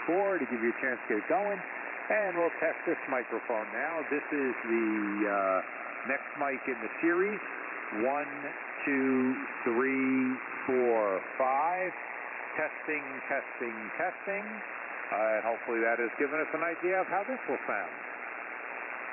All of the tests were conducted in the HF bands including 80, 40 and 10 meters.
• Sound like the RE-320 with EQ off but more bass
• Hard to tell the difference compared to the SM58
AKG D8000M
AKG-D8000M.mp3